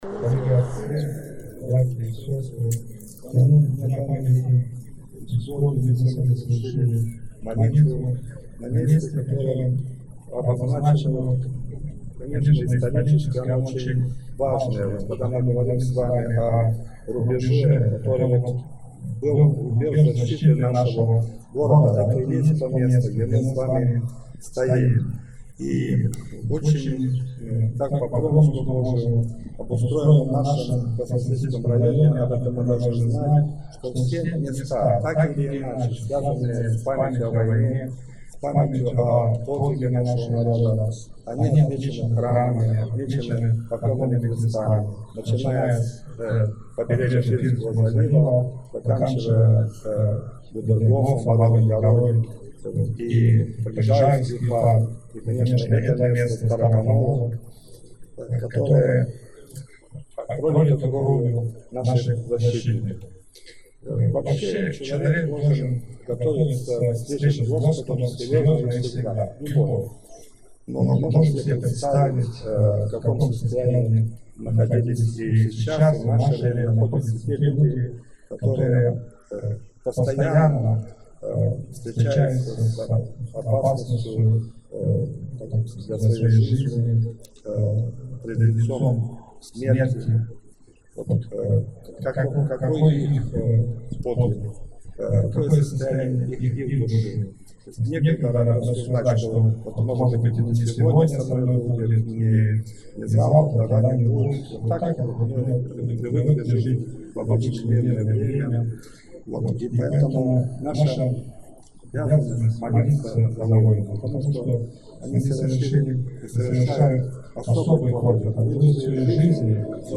Обращение